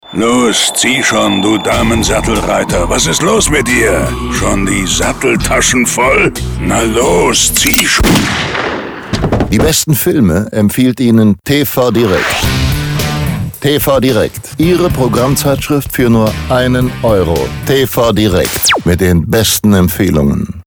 Unser Kunde TV direkt setzt bei der Radiowerbung auf Spotserien und zusätzlichen Remindern.